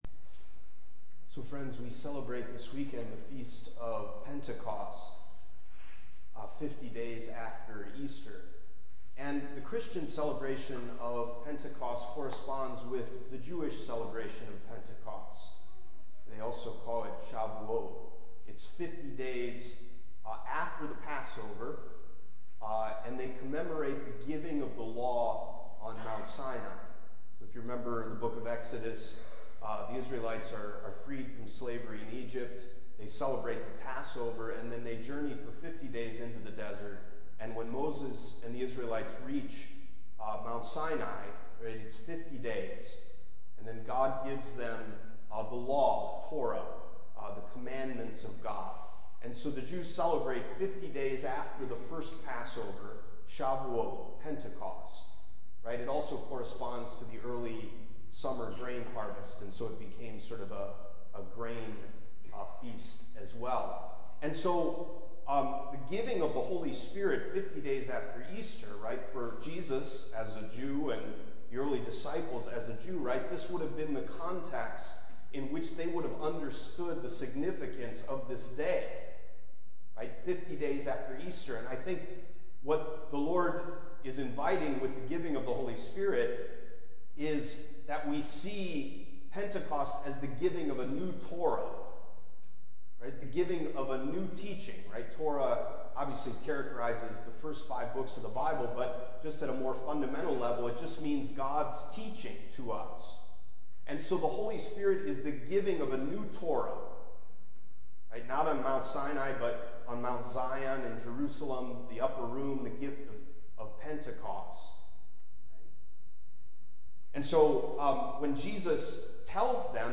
Homily from the weekend of May 28, 2023